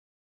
Divergent / mods / Soundscape Overhaul / gamedata / sounds / monsters / rat / voice_1.ogg